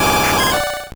Cri de Poissoroy dans Pokémon Or et Argent.